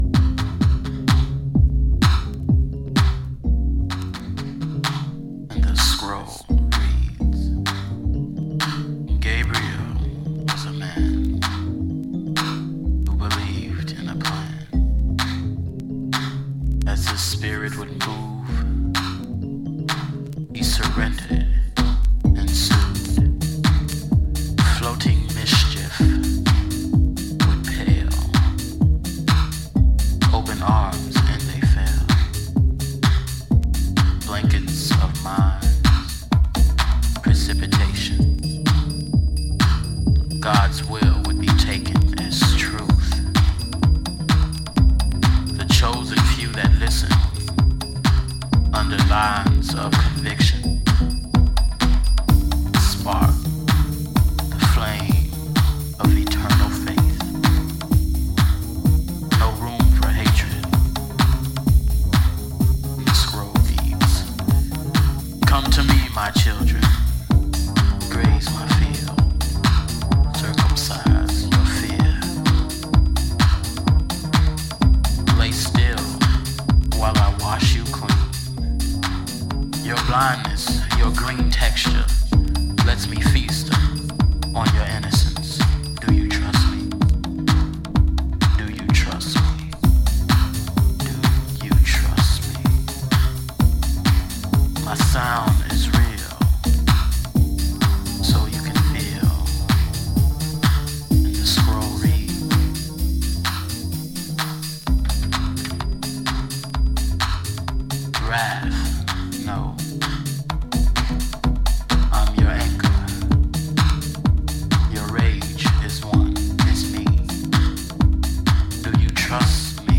ディープで洗練されたソウル/ジャズ感を含んだ傑作ハウス作品です。